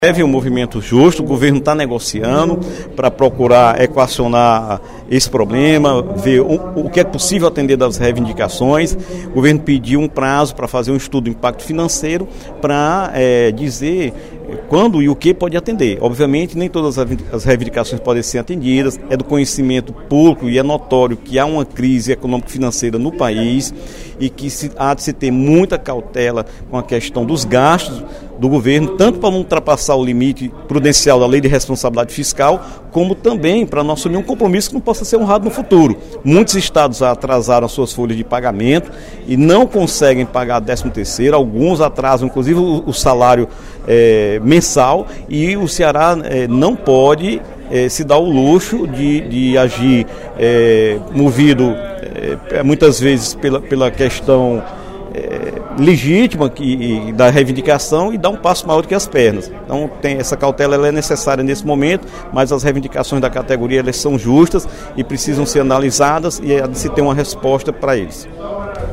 O deputado Dr. Santana (PT) comunicou, durante o primeiro expediente da sessão plenária desta quinta-feira (02/06), a realização de reunião, na semana passada, entre o secretário de Assuntos Institucionais do Governo do Estado, Nelson Martins, com funcionários em greve da Agência de Defesa Agropecuária (Adagri), no sentido de manter aberto o canal de negociações para o atendimento das demandas dos servidores.